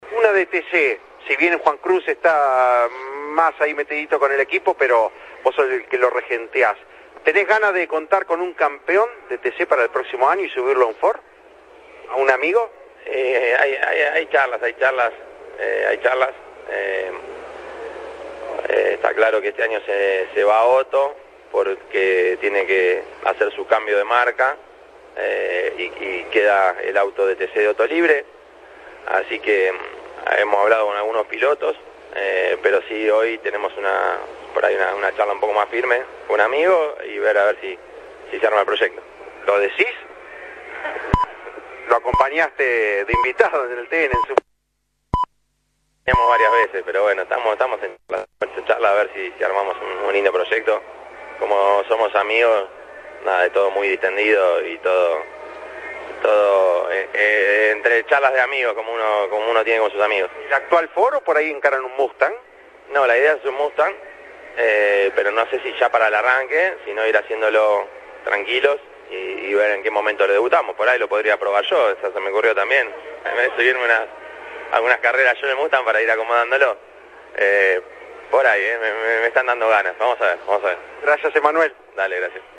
Durante la cita que el Turismo Carretera tuvo en El Calafate, Emanuel Moriatis, presidente de la categoría, habló con Campeones durante la transmisión en Radio Continental y contó el proyecto que tiene en carpeta para el futuro del Ford que dejará Otto Fritzler (Se suma al Pradecon Racing) la próxima temporada.
Escuchá la nota con Emanuel Moriatis: